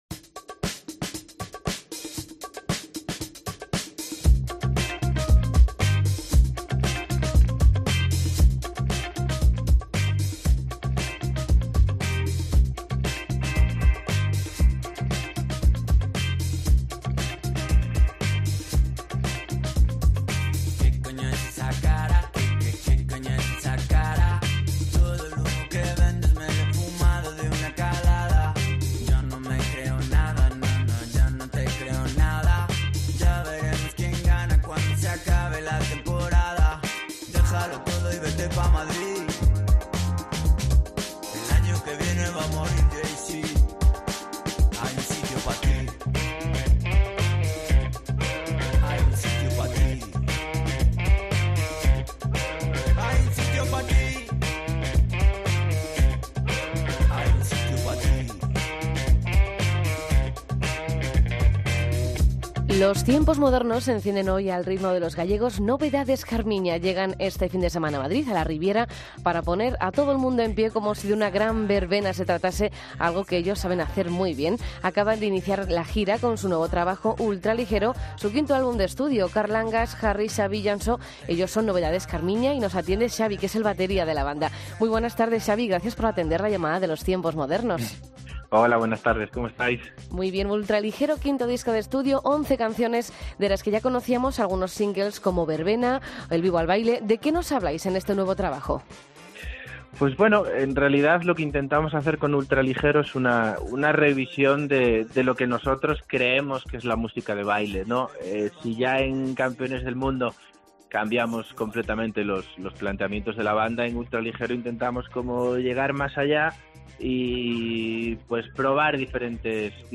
Entrevista a Novedades Carminha en los Tiempos Modernos